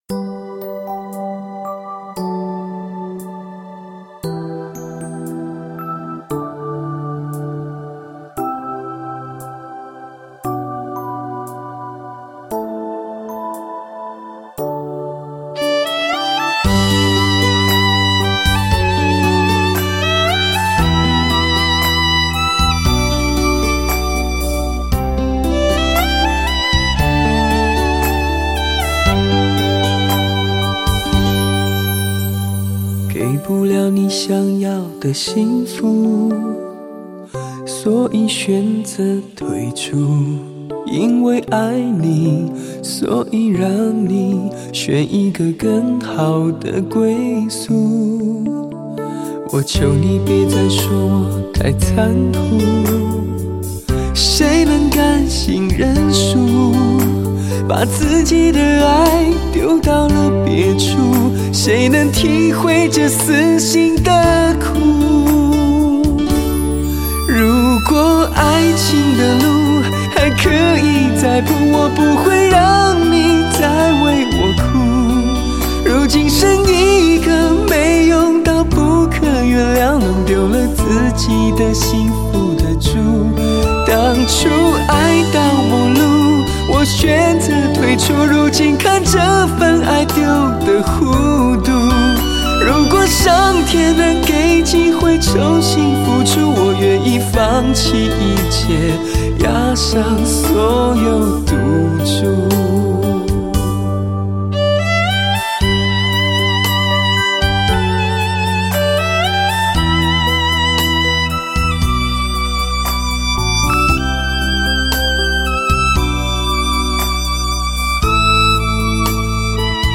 台湾白金录音棚录制